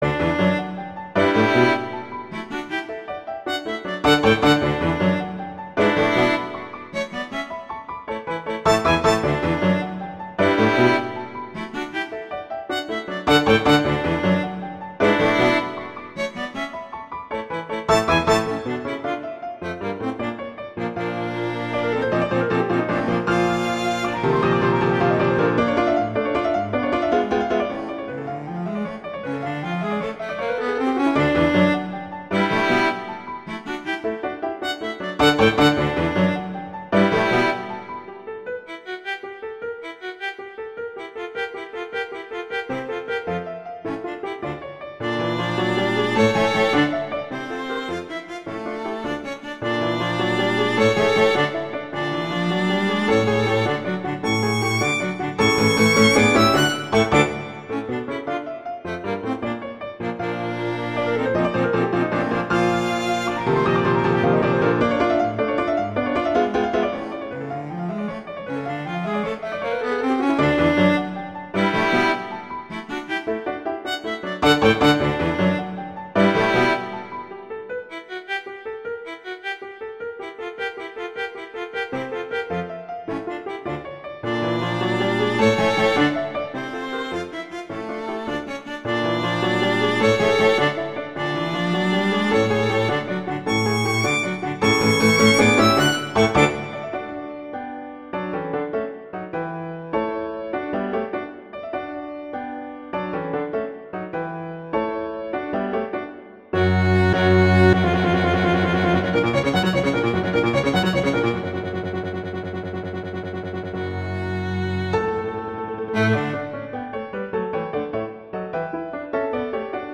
classical
Half Note =156 BPM (real metronome 152 BPM)